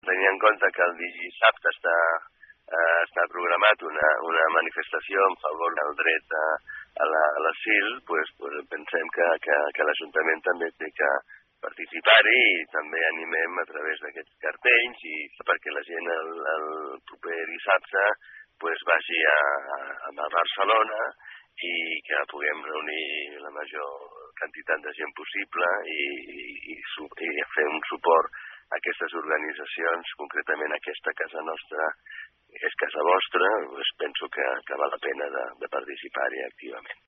D’aquesta manera, el consistori vol encoratjar i convidar als palafollencs a què assisteixin a l’acte d’aquest dissabte 18. Ho explica l’alcalde de Palafolls, Valentí Agustí.